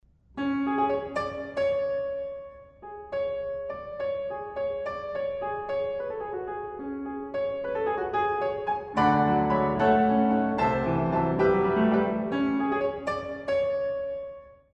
in F-Sharp Minor